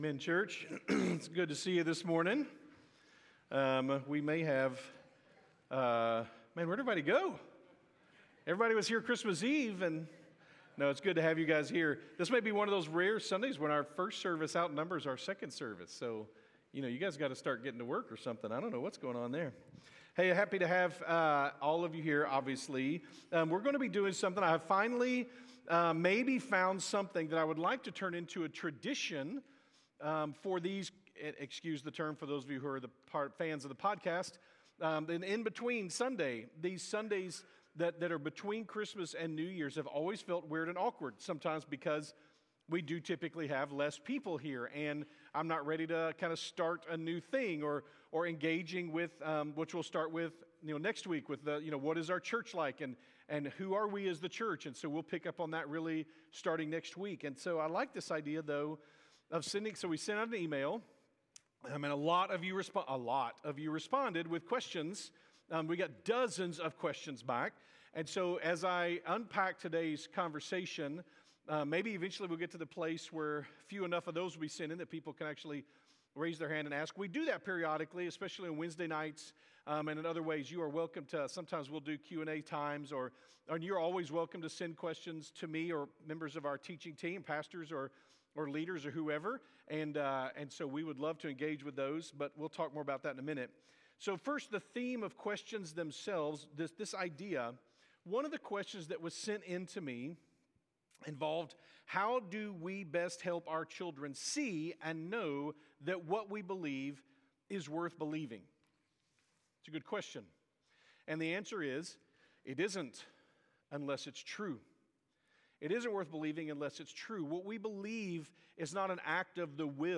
2025 Who we Are – Q&A